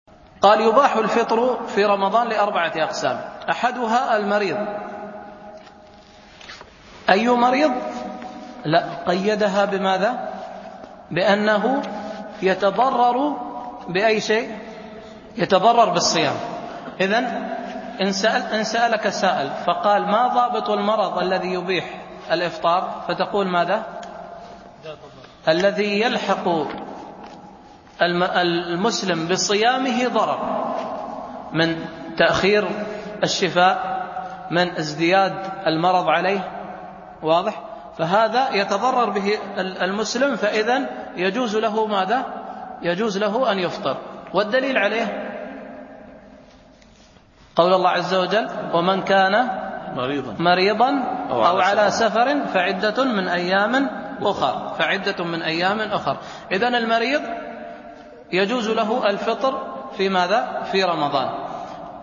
الألبوم: دروس مسجد عائشة (برعاية مركز رياض الصالحين ـ بدبي) المدة: 1:02 دقائق (268.06 ك.بايت) التنسيق: MP3 Mono 22kHz 32Kbps (VBR)